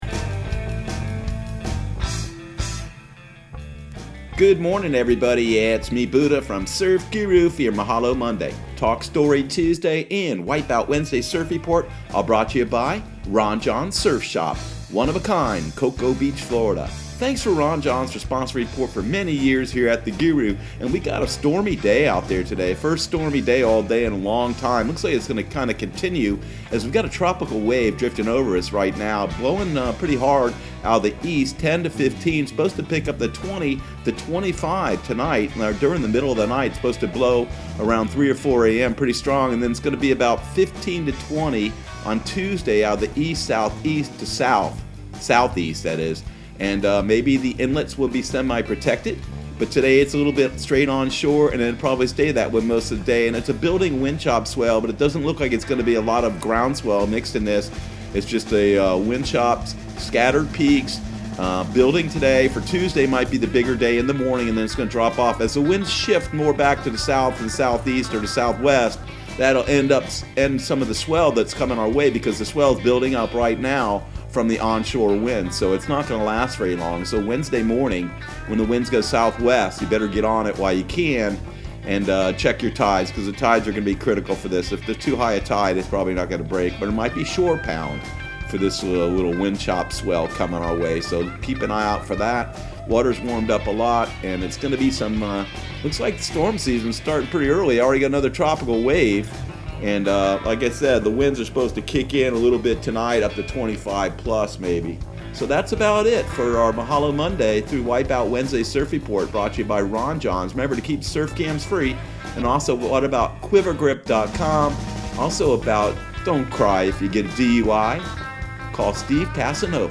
Surf Guru Surf Report and Forecast 05/25/2020 Audio surf report and surf forecast on May 25 for Central Florida and the Southeast.